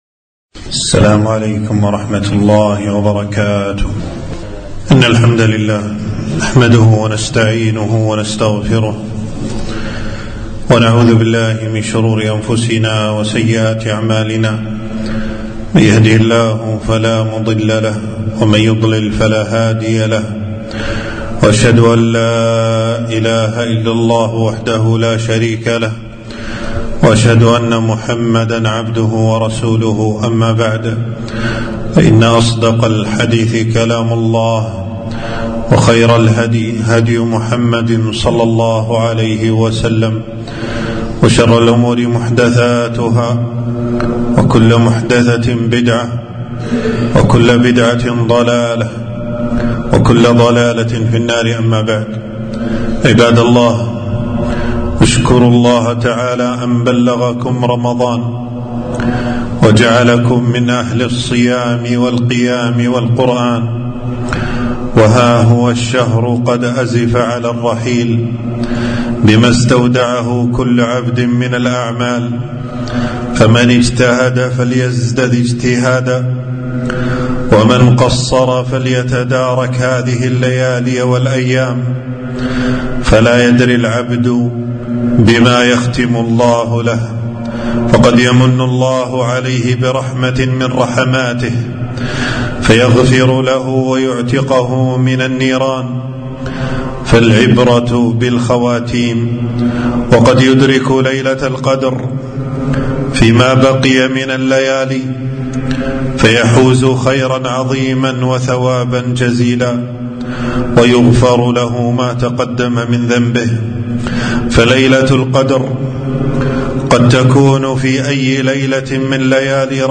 خطبة - اختموا شهركم بعبادة ربكم